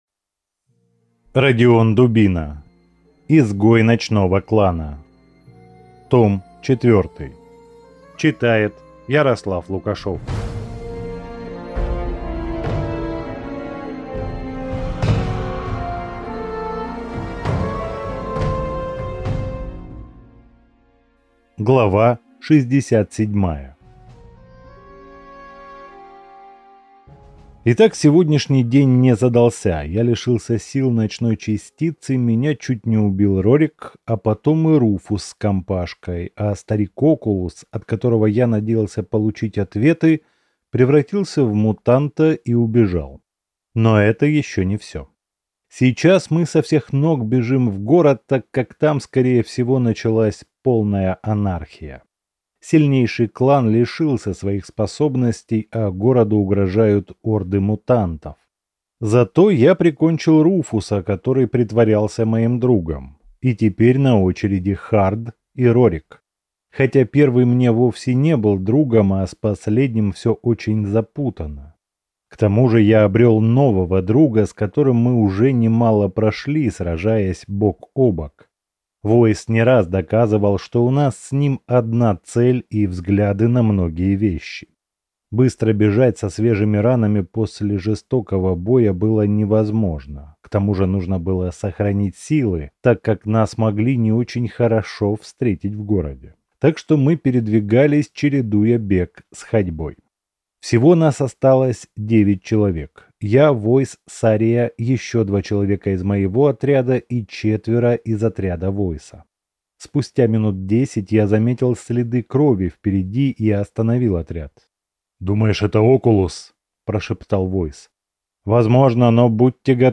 Аудиокнига Изгой ночного клана (том 4) | Библиотека аудиокниг